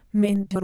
MinderDiphone.wav